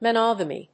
音節mo・nog・a・my 発音記号・読み方
/mənάgəmi(米国英語), mʌˈnɑ:gʌmi:(英国英語)/